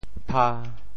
调: 低 潮州府城POJ pha 国际音标 [p]